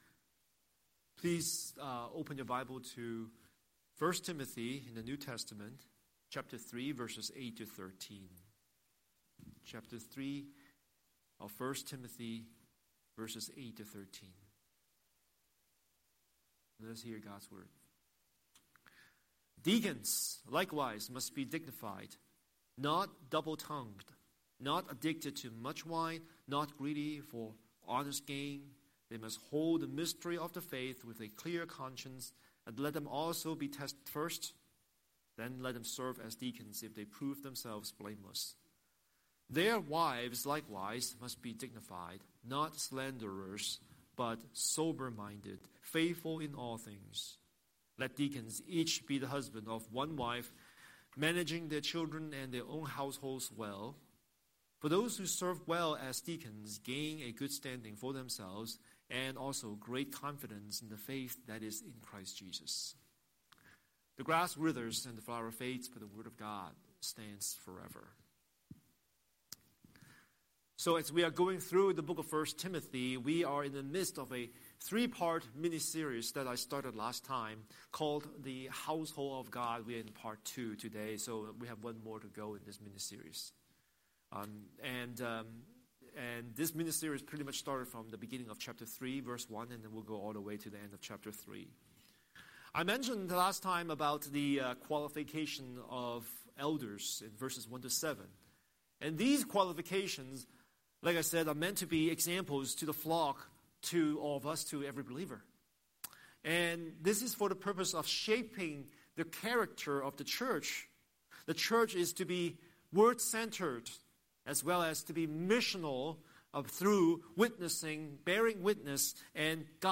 Scripture: 1 Timothy 3:8-13 Series: Sunday Sermon